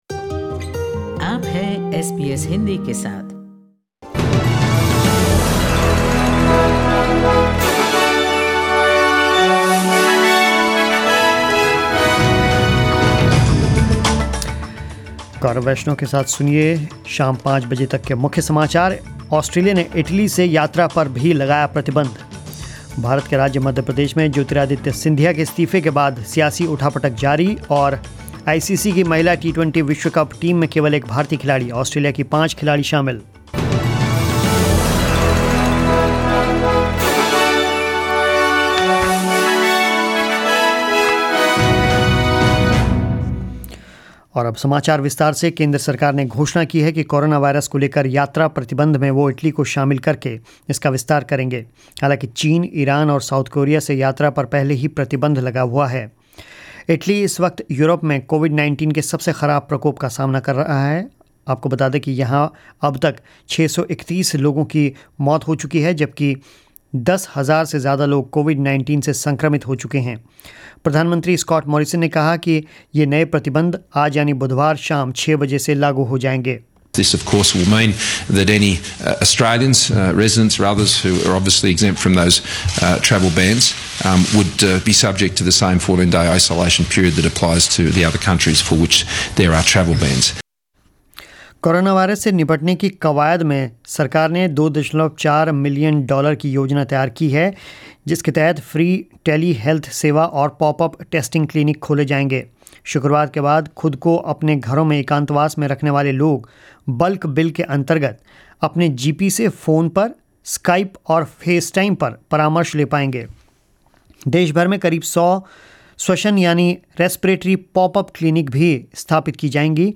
News in Hindi 11 March 2020